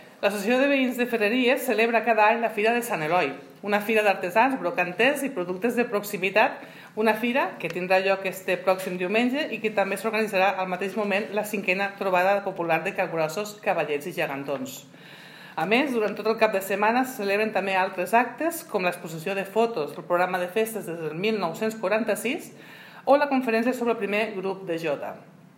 La regidora Sònia Ruperez ha assenyalat les activitats més destacades que se duran a terme este cap de setmana.